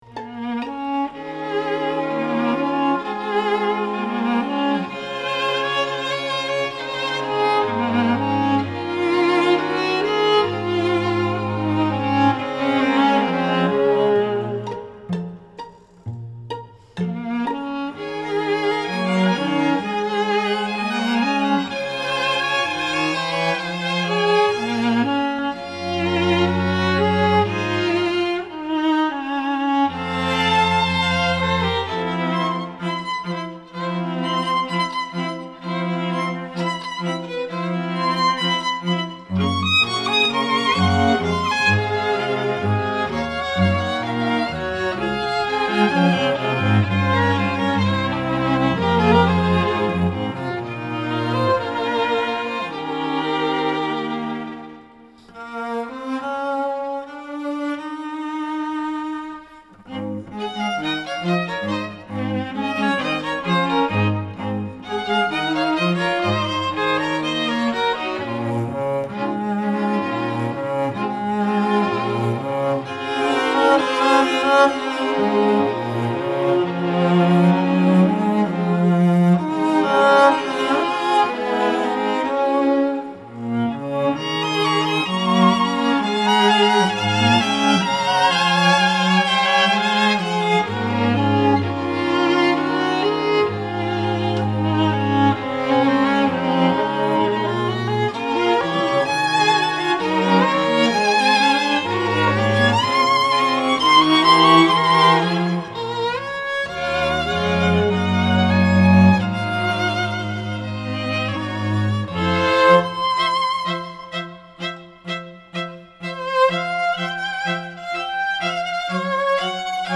violin, cello, piano, flute, acoustic guitar, harp, chimes
Listen to Fun Songs for the Flower Girl and Ring Bearer